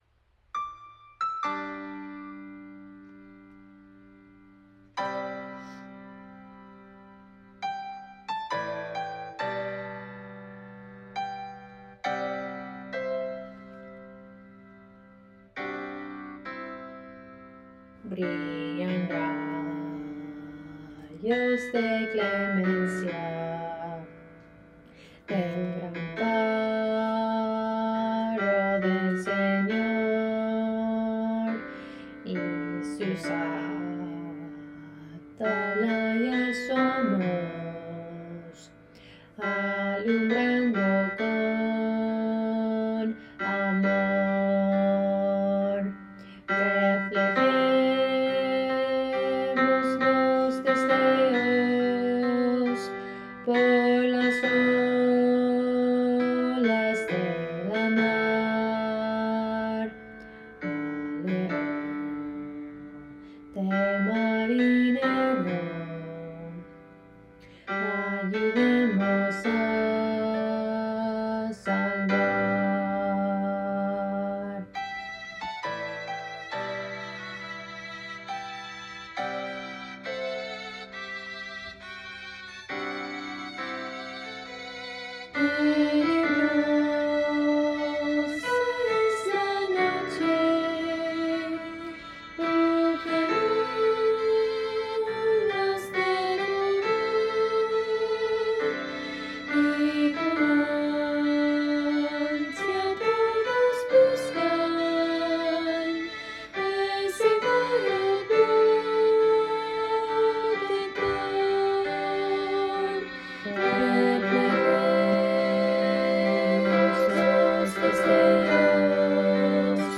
Voicing/Instrumentation: SAT